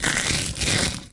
紧缩7
描述：各种Triscuits用手捏碎。 使用Zoom H2.
标签： 裂化 紧缩 粉碎
声道立体声